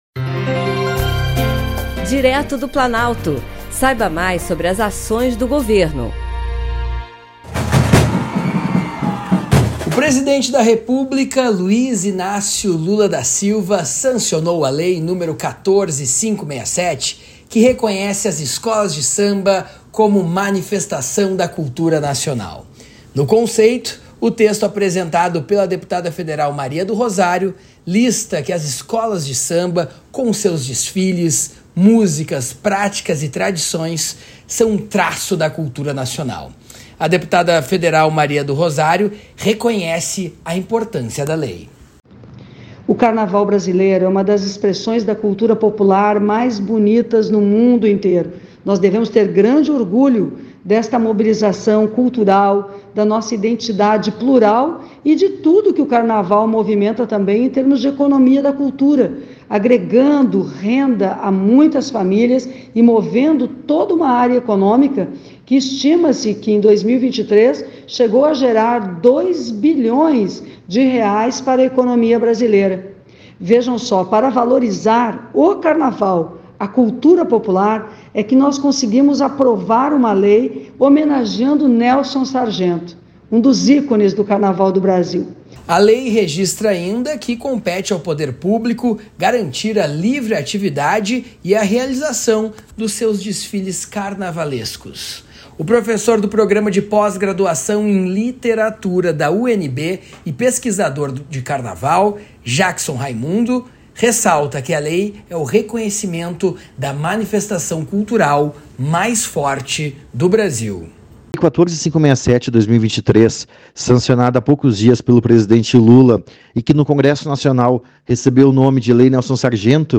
Boletins de Rádio
A deputada federal Maria do Rosário reconhece a importância da Lei.